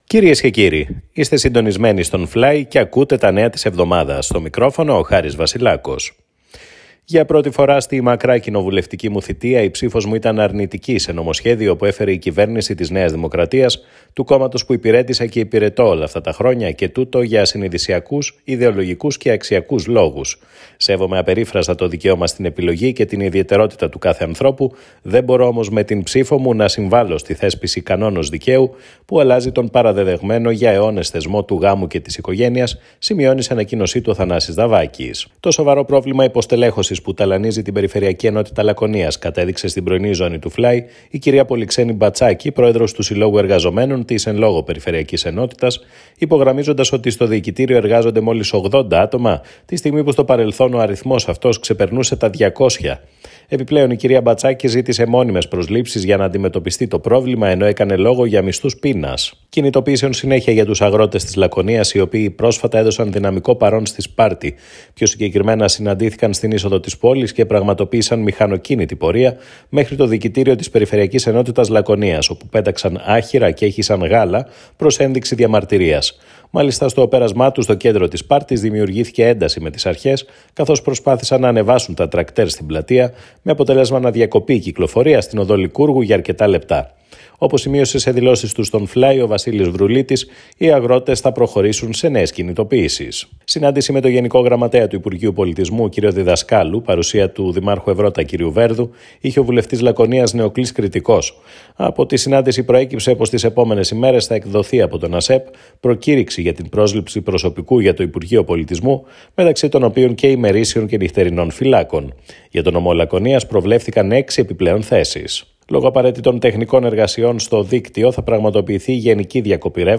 Δελτίο-ειδήσεων-1.m4a